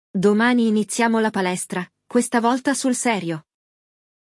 Hoje, vamos acompanhar um diálogo entre um casal que decide finalmente retomar os treinos e marcar o primeiro dia de academia.
1. Escute atentamente o diálogo para captar a entonação e a pronúncia natural dos falantes nativos.
Durante o episódio, há momentos de repetição guiada, onde você escuta e pratica a pronúncia das palavras e frases.